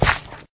gravel1.ogg